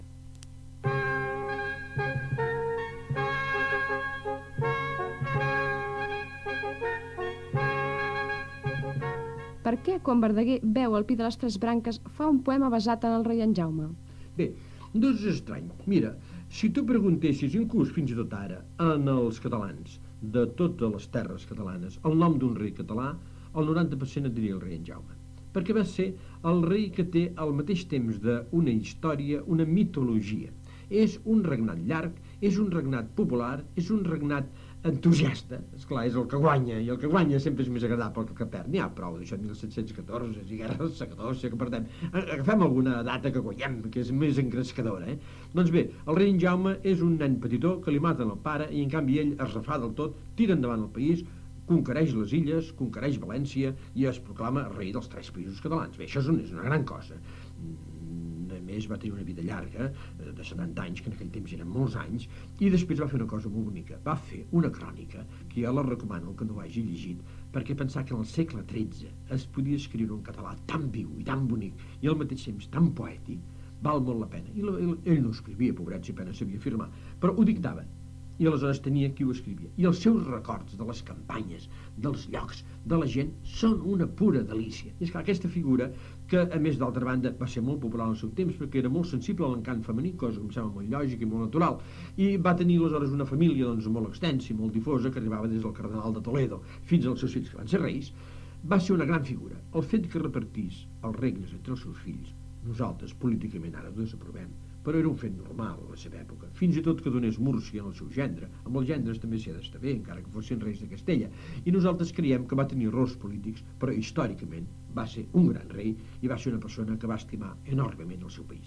Josep Maria Ainaud de Lasarte parla del rei en Jaume, a partir d'un poema de Jacint Verdaguer.